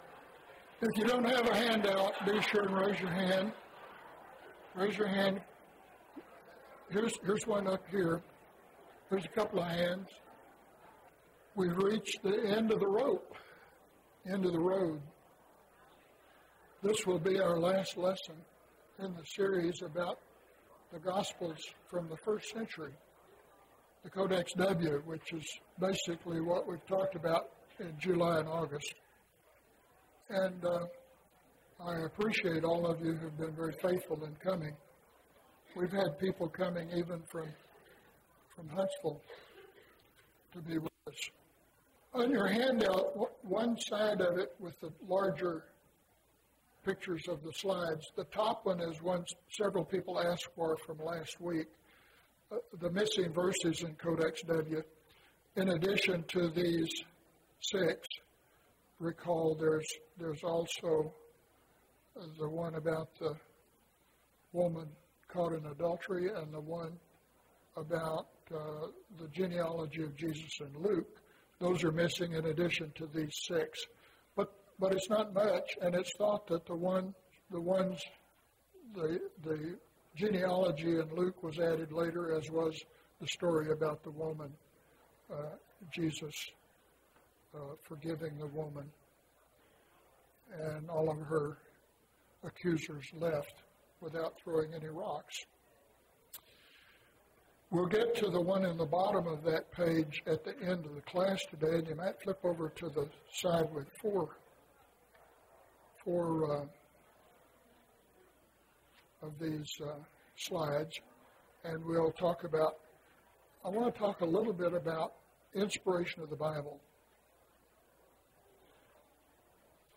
Summary and Conclusions (14 of 14) – Bible Lesson Recording